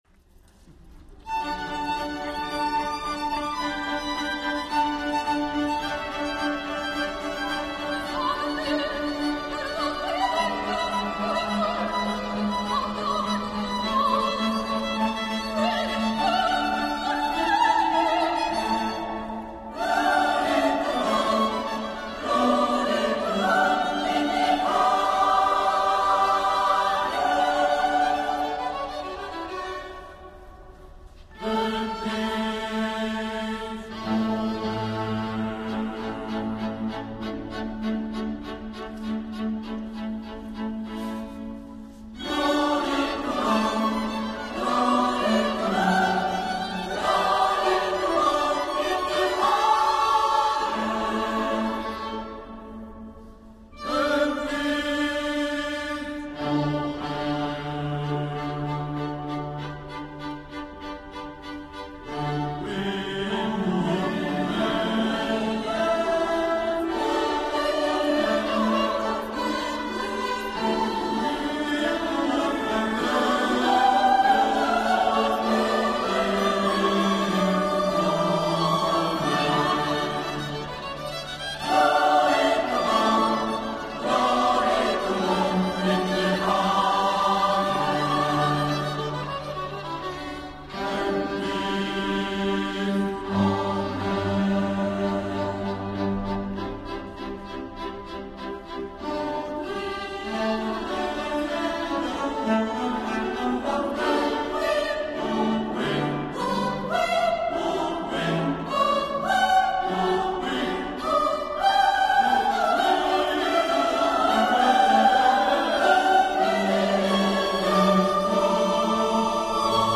Glory to God Coro “S. Cecilia” di Calvisano e Gruppo d’Archi “Vergilius” < brano precedente brano Successivo >